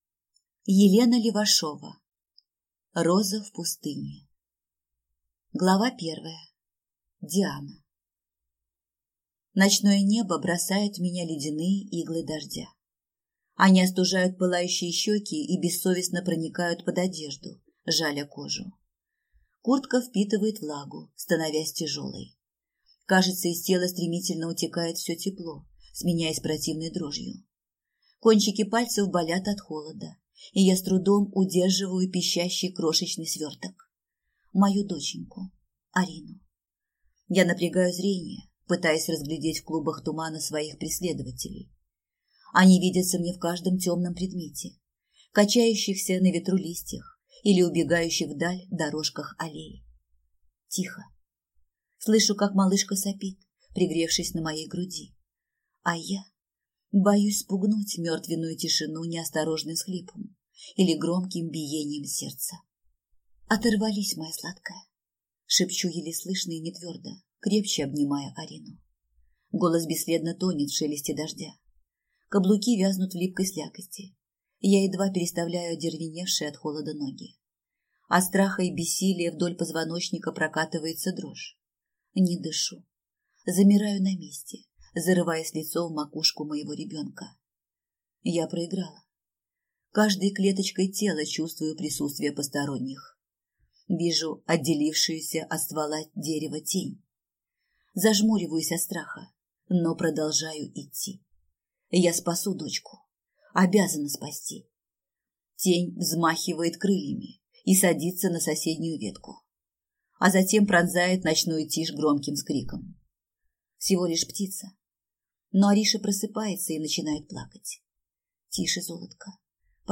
Аудиокнига Роза в пустыне | Библиотека аудиокниг